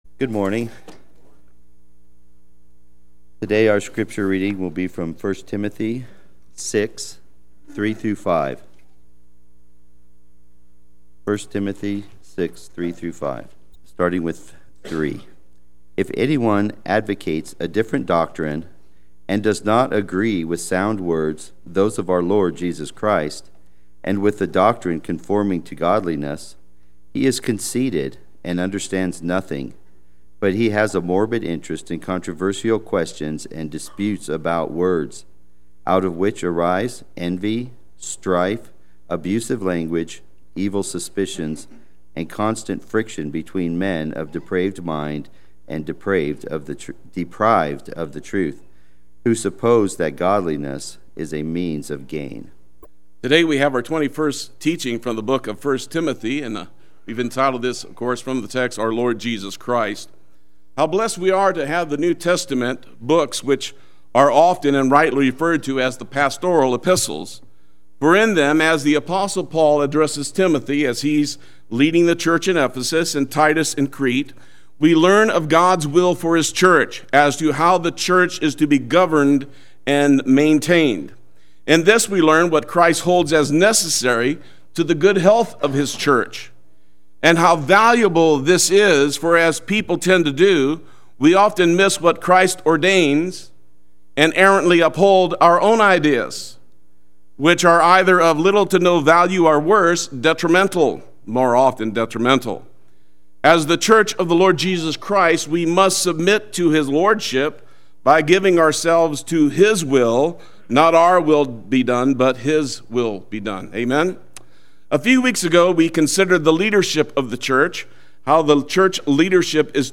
Play Sermon Get HCF Teaching Automatically.
Our Lord Jesus Christ Sunday Worship